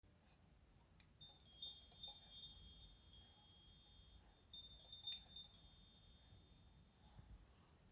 De Sparkle is een instrument dat heel lichte tingelende geluiden maakt.